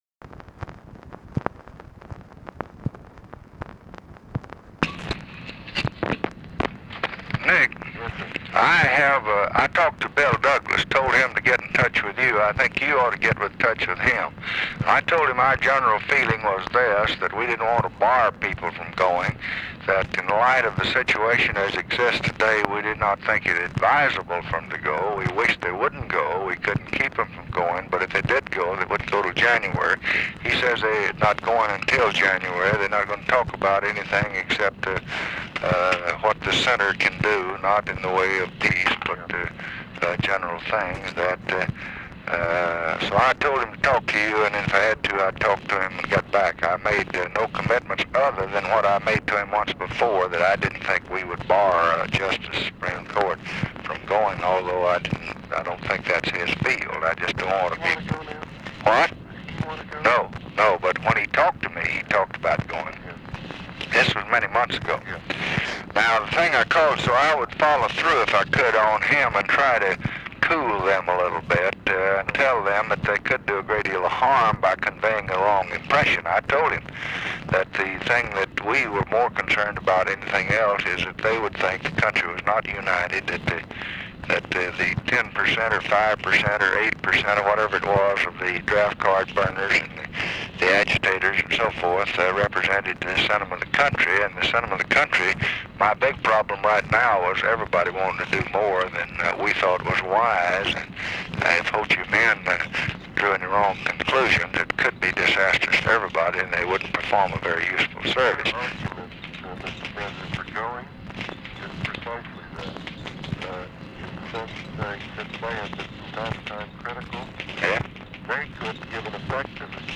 Conversation with NICHOLAS KATZENBACH, December 8, 1966